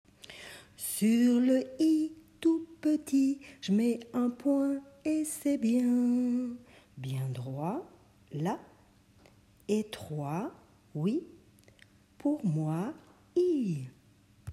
Merci de votre indulgence pour les enregistrements improvisés !
Chanson :
I-chanté.m4a